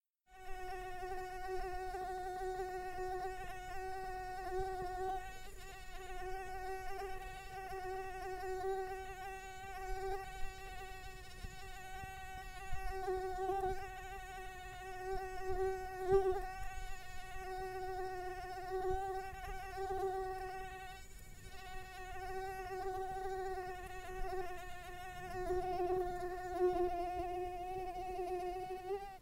insect.mp3